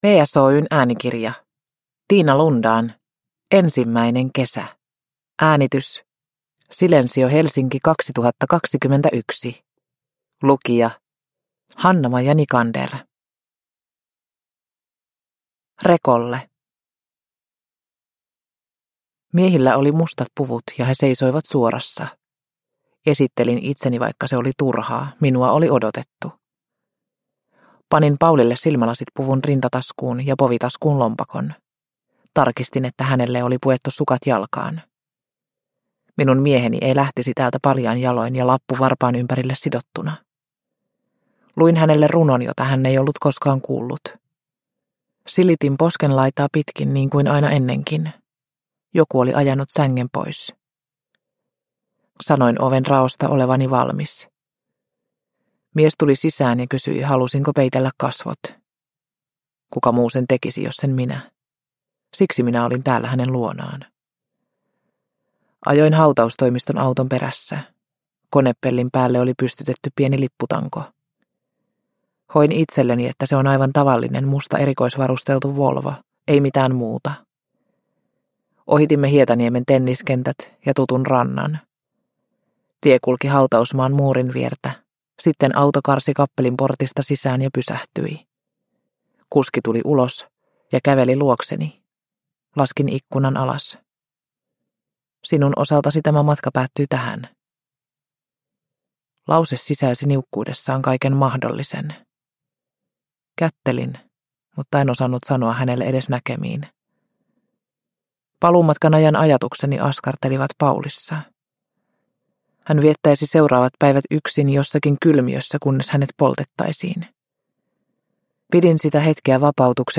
Ensimmäinen kesä – Ljudbok – Laddas ner